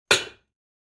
Kitchen Pot Set Down Wav Sound Effect #2
Description: The sound of putting a kitchen pot on the stove
Properties: 48.000 kHz 16-bit Stereo
Keywords: kitchen pot, kettle, pan, place, placing, put, set, down, cooking, counter, stove
pot-set-down-preview-2.mp3